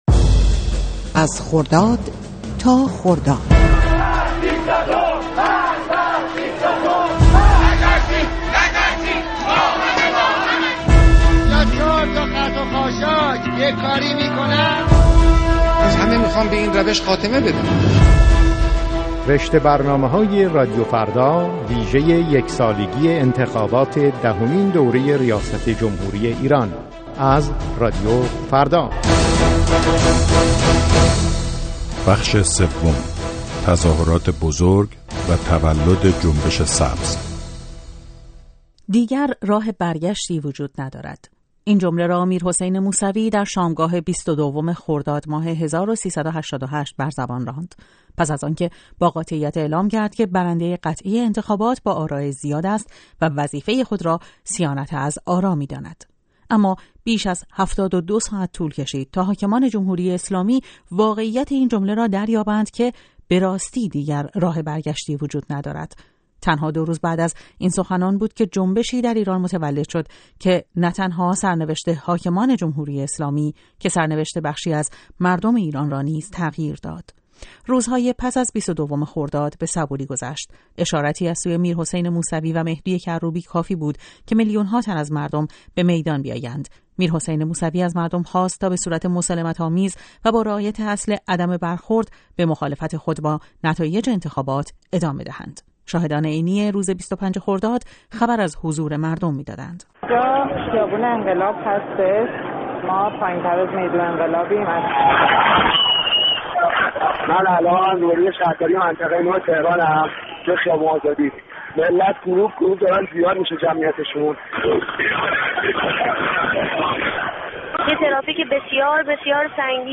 گزارش رادیویی خرداد تا خرداد (بخش سوم): تظاهرات بزرگ و تولد جنبش سبز